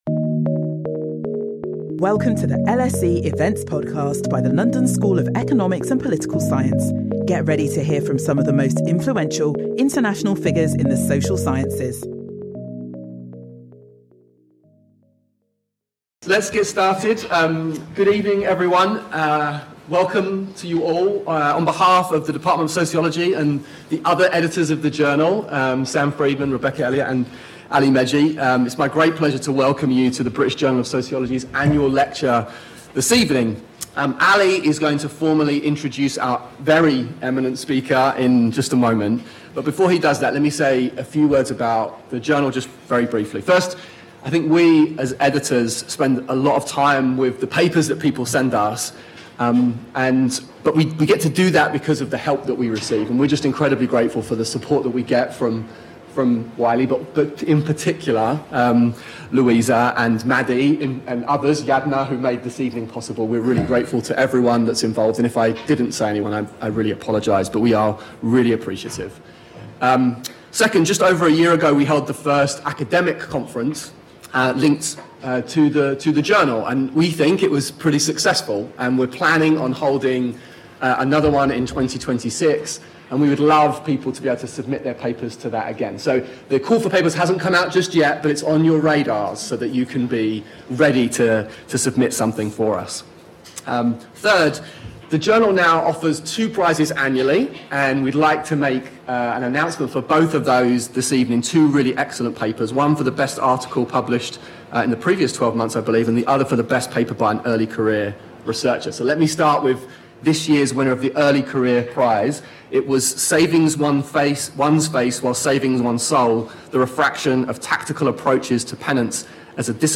In this year’s annual British Journal of Sociology lecture, Eduardo Bonilla-Silva will review the basics of his "racialized social system" with a focus on explaining how he has improved the theoretical apparatus over the years.